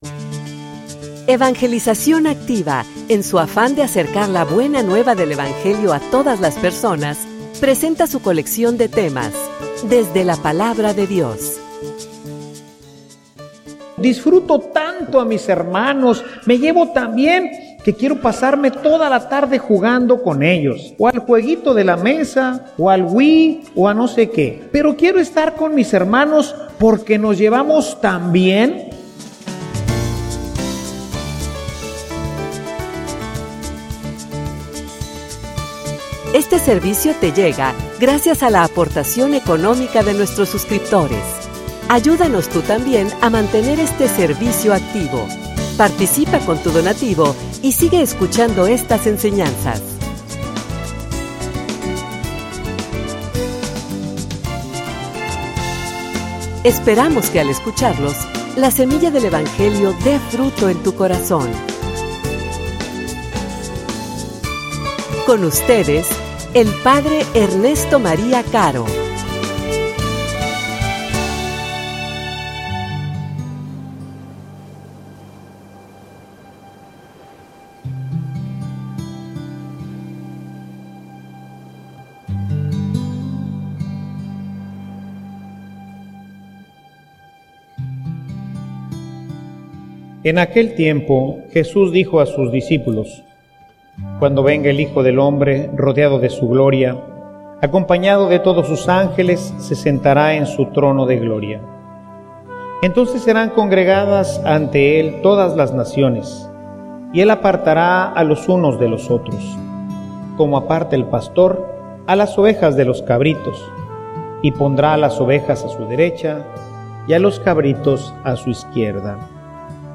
homilia_Un_maravilloso_rey.mp3